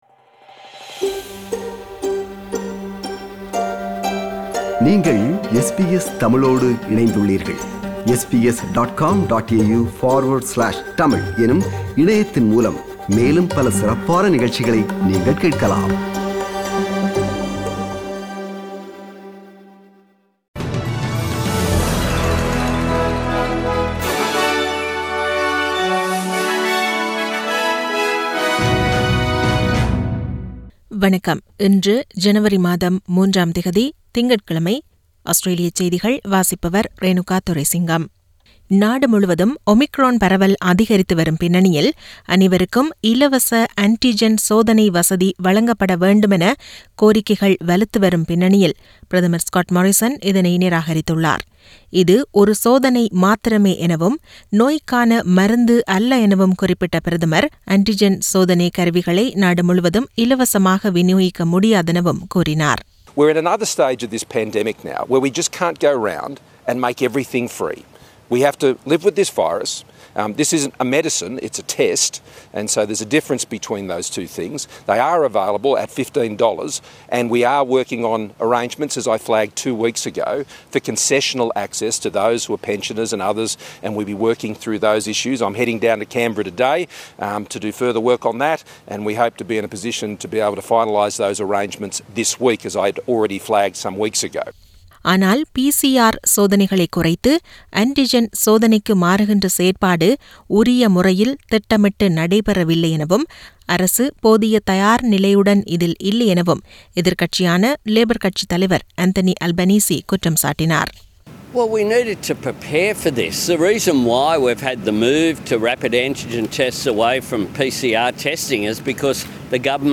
Australian news bulletin for Monday 3 Jan 2022.